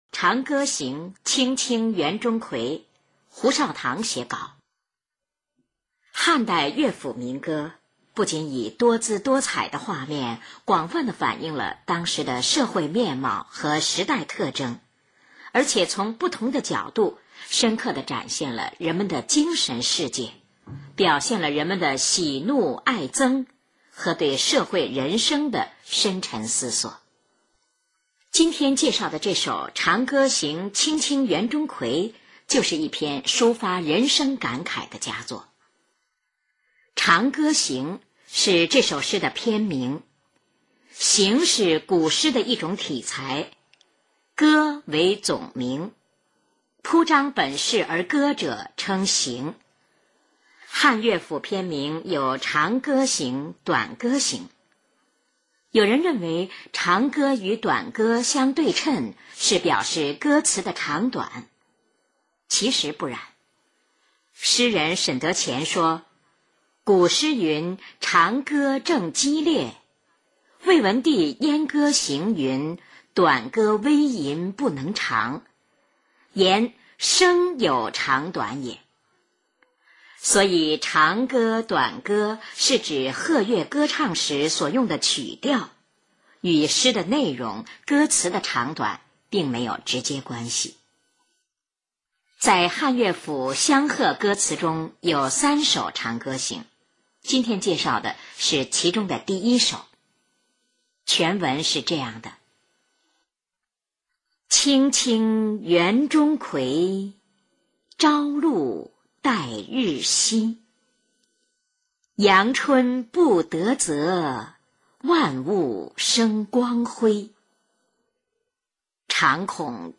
印象最深的是后来人们概括的“三名”：名人介绍名作，由名播音员广播。